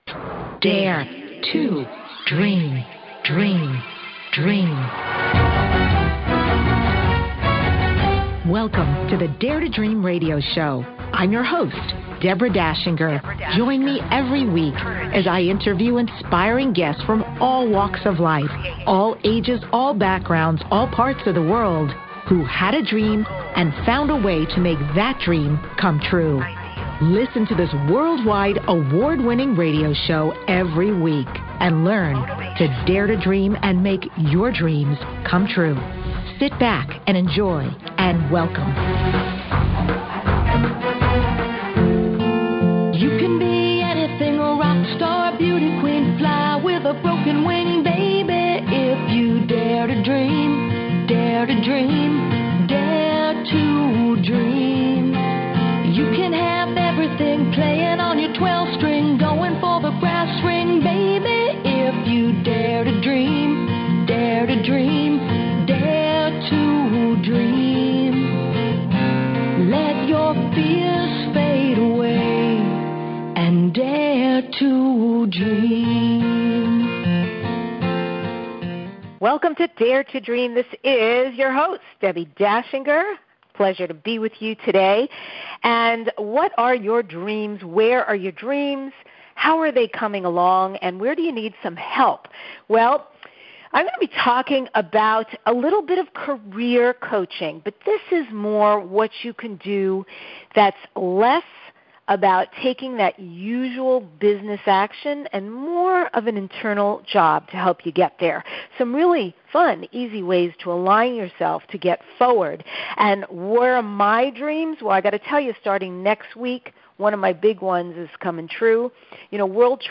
Talk Show Episode, Audio Podcast, Dare To Dream and Guest